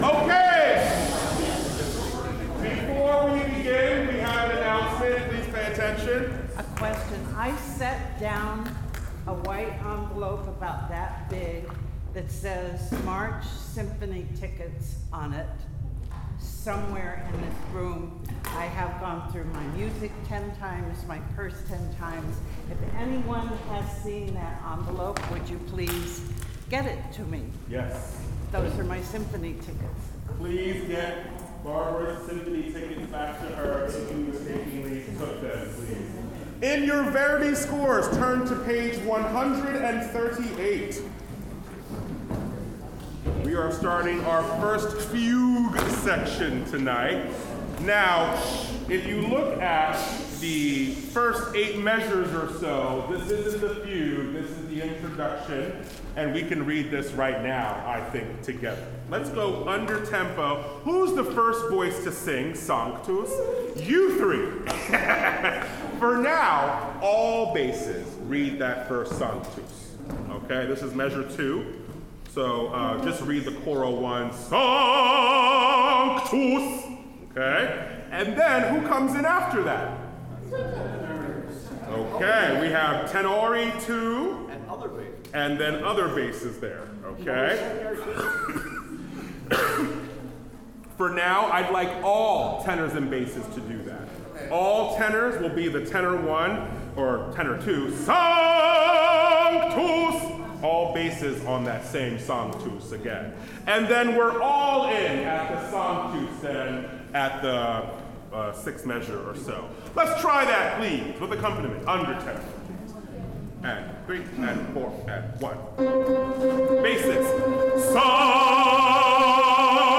02:07 Warm-ups and solfege instructions